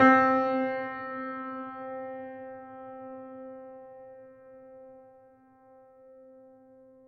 piano-sounds-dev
Steinway_Grand
c3.mp3